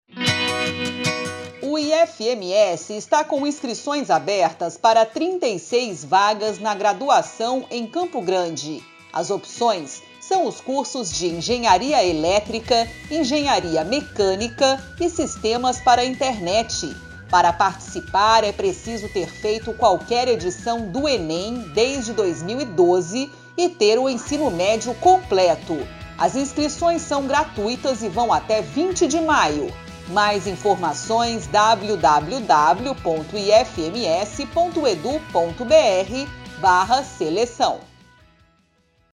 Spot - Cursos de graduação para o 2º semestre de 2022 em Campo Grande
Áudio enviado às rádios para divulgação institucional do IFMS.